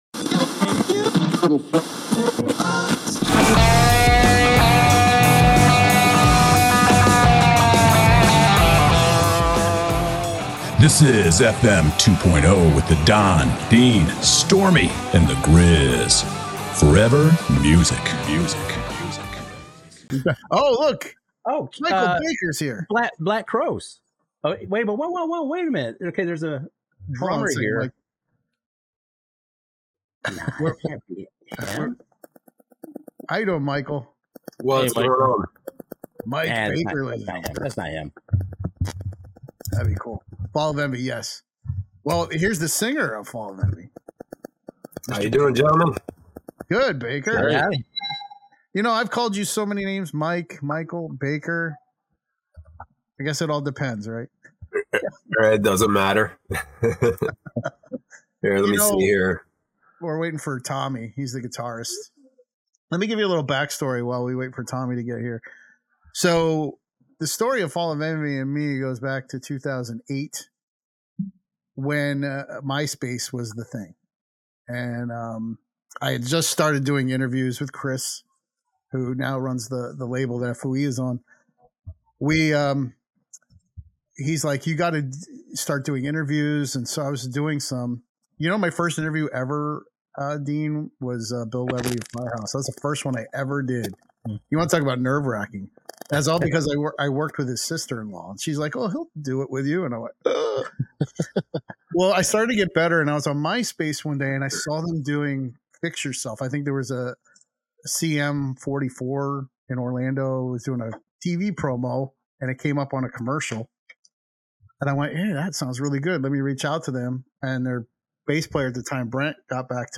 We dig into songs from their debut album "Poetic Rage" that we did not cover in the first interview. We also get into their latest single "Left For Dead".